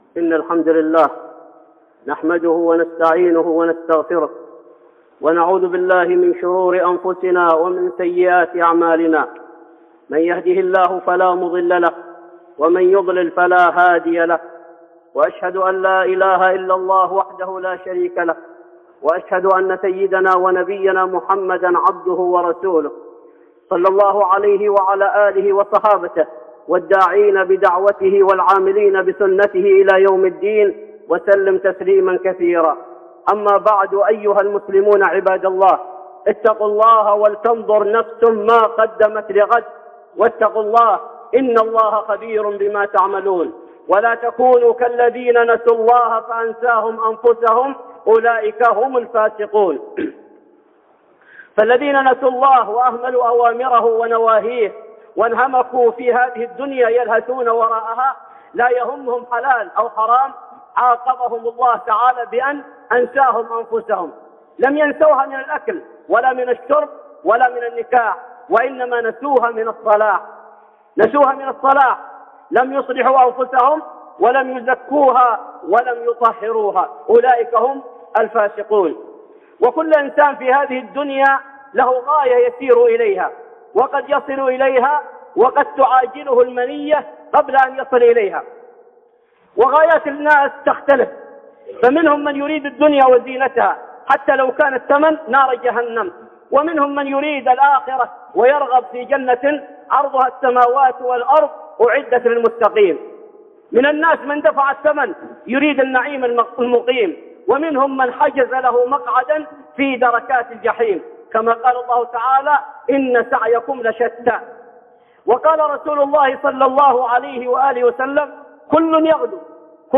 (خطبة جمعة) كل يغدوا فبايع نفسه فمعتقها وموبقها